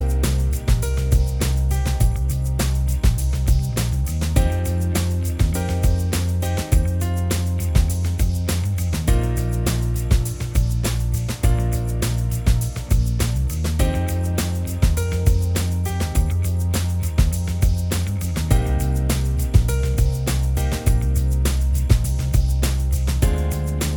Minus All Guitars Pop (2000s) 4:47 Buy £1.50